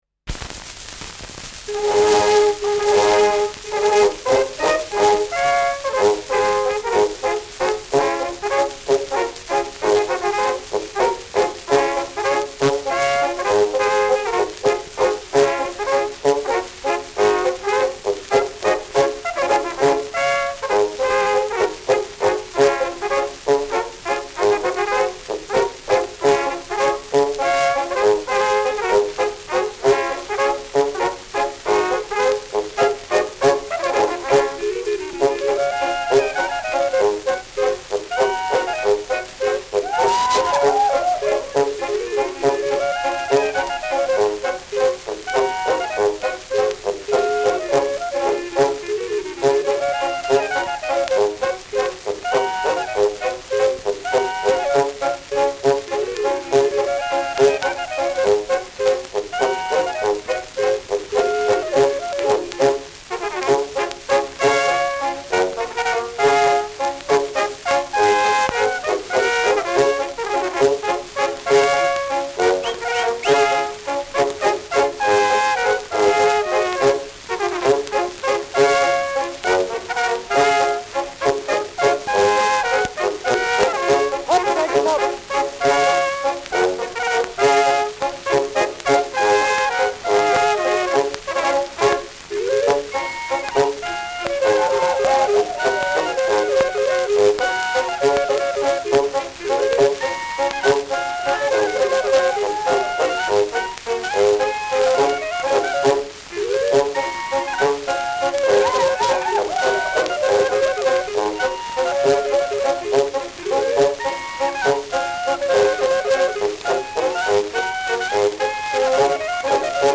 Schellackplatte
präsentes Rauschen : präsentes Knistern : abgespielt : leiert : gelegentliches „Schnarren“ : vereinzeltes Knacken
Pfiffe, Zwischenrufe, Juchzer.
[Salzburg] (Aufnahmeort)